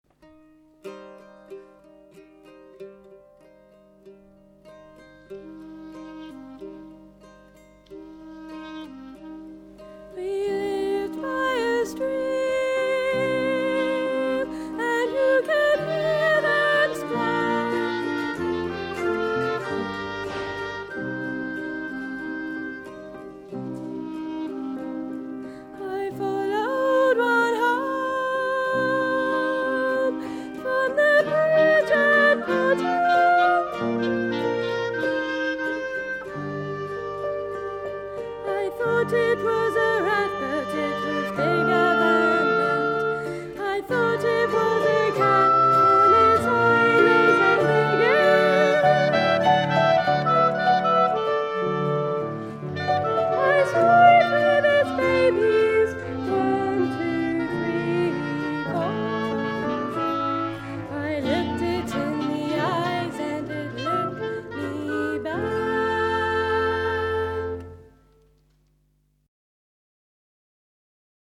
WCT joined other singers to perform the choral works as part of Otter – Lutra, Lutra on the Stour, in Wimborne Minster and Guildford Cathedral.